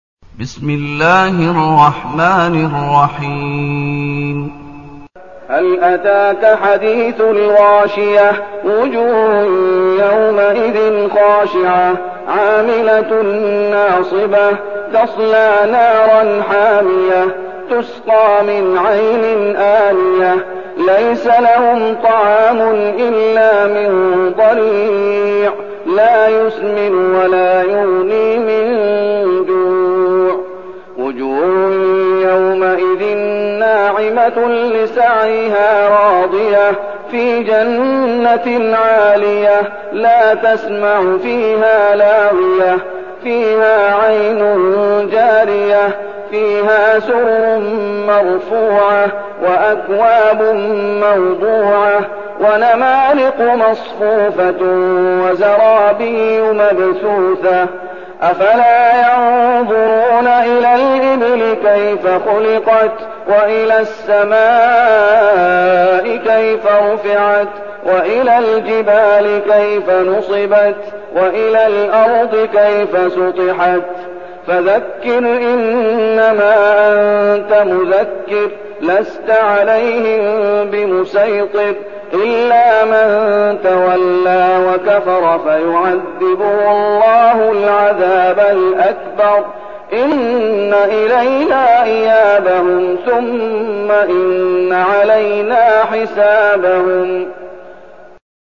المكان: المسجد النبوي الشيخ: فضيلة الشيخ محمد أيوب فضيلة الشيخ محمد أيوب الغاشية